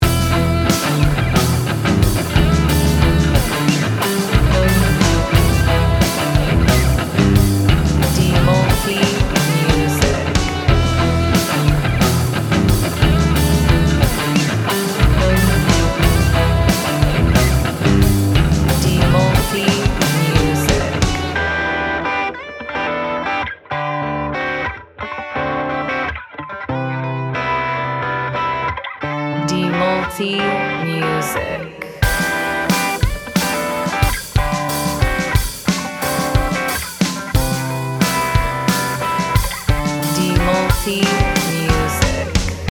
Gym Music Instrumental
Lagu ini punya vibe santai tapi tetap bertenaga.